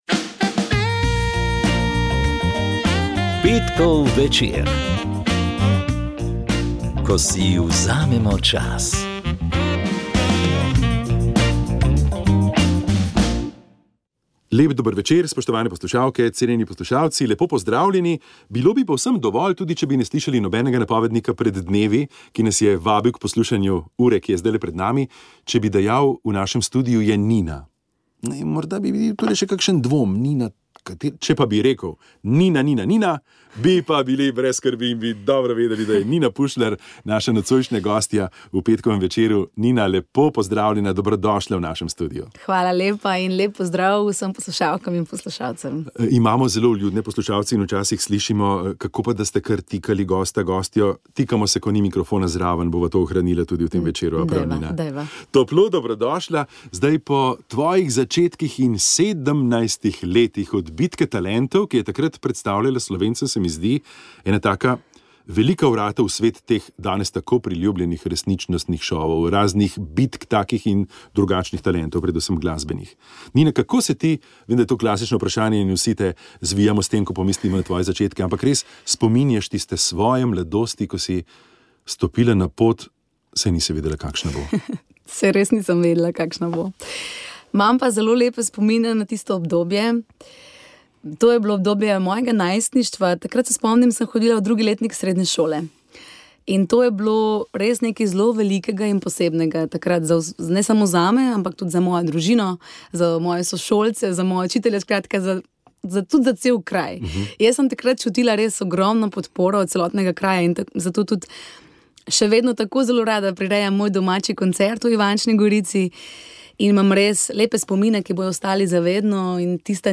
V naš studio je prišla Nina Pušlar. Spregovorila je o začetkih in bogati poti do Hale Tivoli, kamor decembra vabi na koncert.